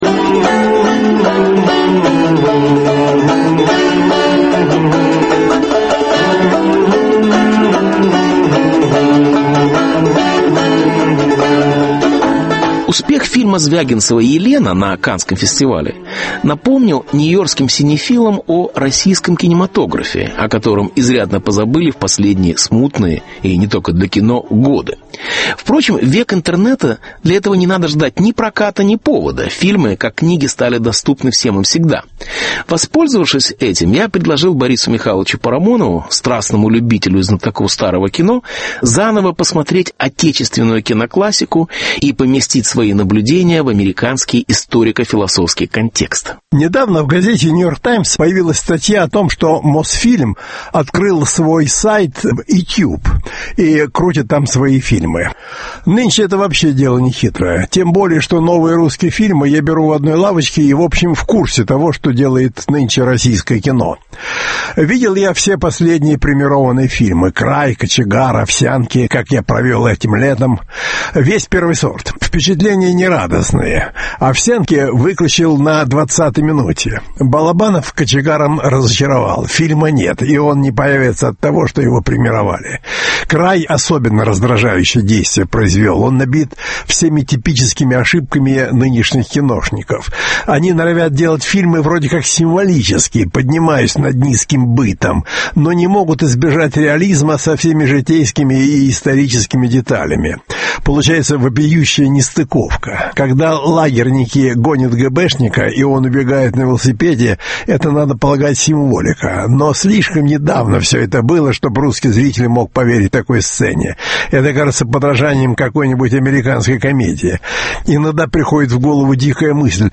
«Мосфильм на Гудзоне»: российское кино в американском контексте. Беседа с Борисом Парамоновым.